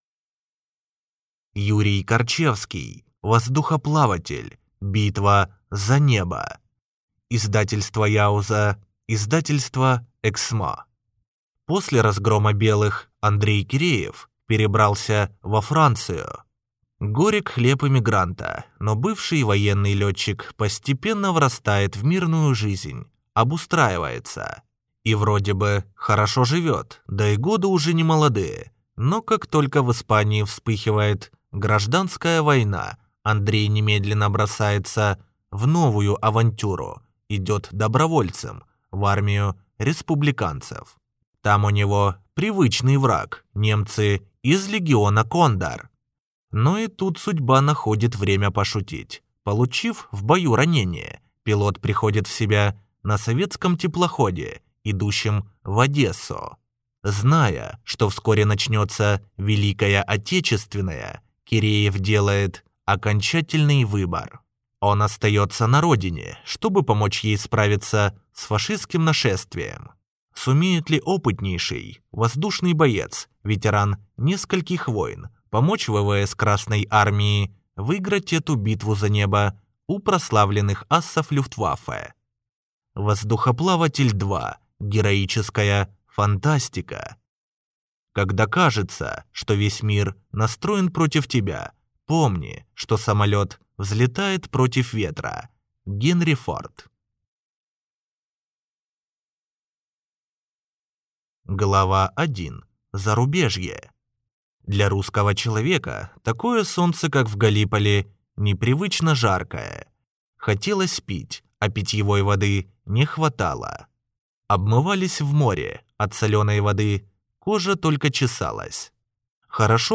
Аудиокнига Воздухоплаватель. Битва за небо | Библиотека аудиокниг